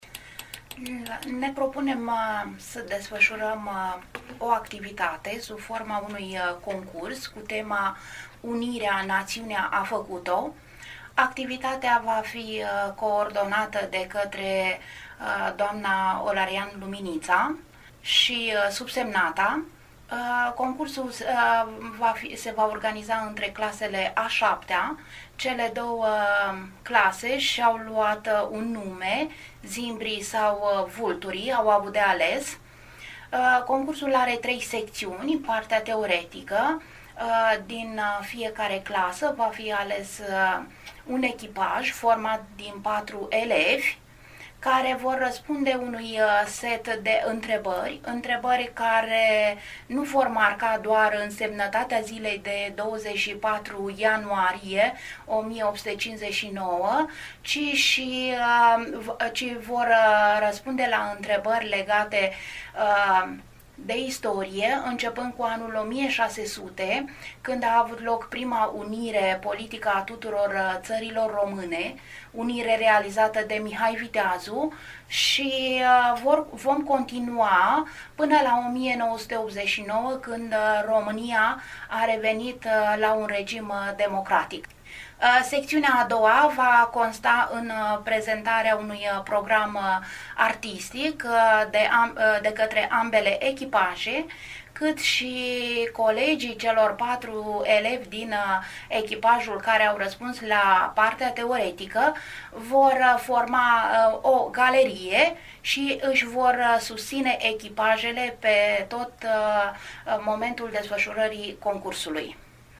Cei 158 de ani de la Unirea Principatelor Române, Moldova și Țara Românească, sub domnitorul Alexandru Ioan Cuza, au fost marcați și la VIVA FM Rădăuți, printr-o ediție specială.